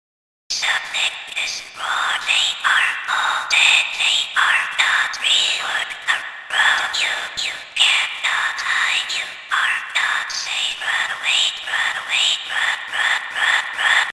horror mode mr fun computer sprunki Meme Sound Effect
Category: Games Soundboard